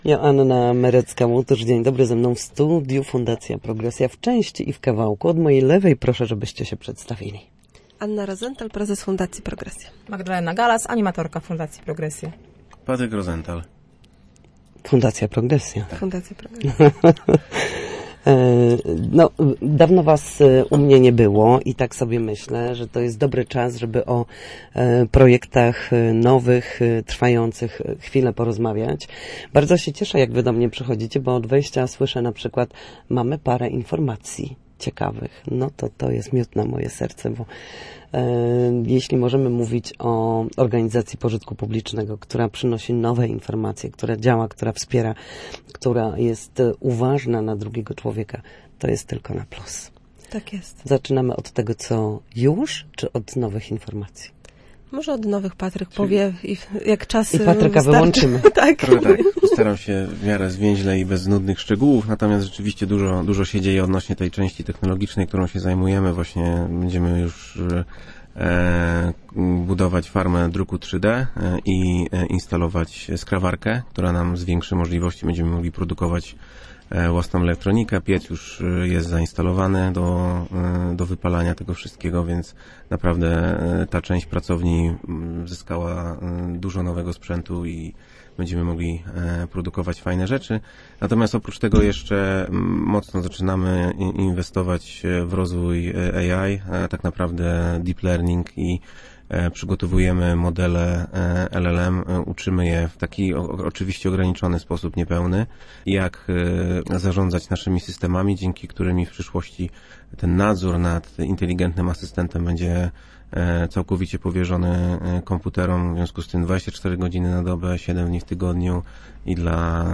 Nowe technologie i wsparcie osób z niepełnosprawnościami. Posłuchaj rozmowy o działalności Fundacji Progresja